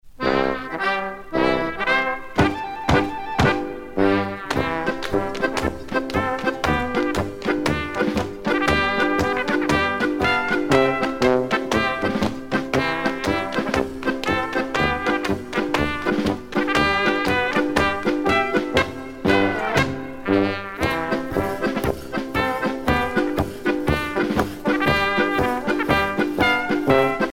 danse : schuhplattler (Bavière)
Pièce musicale éditée